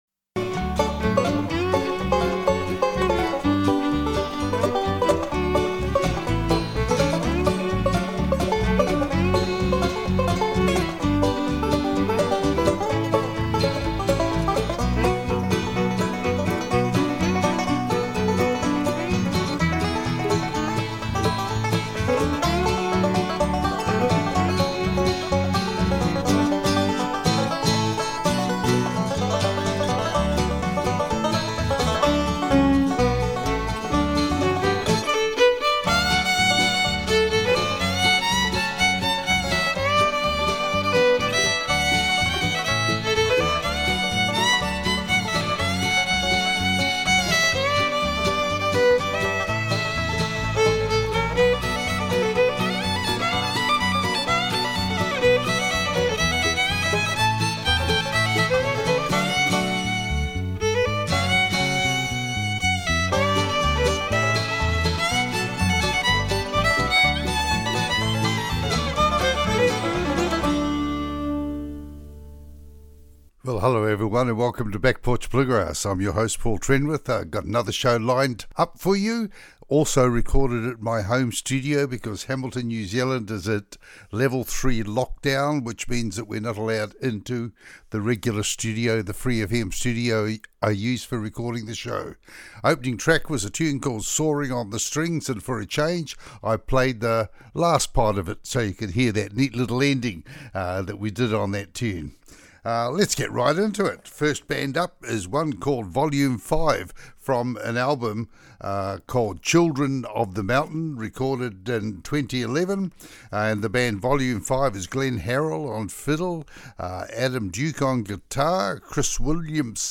Back Porch Bluegrass Show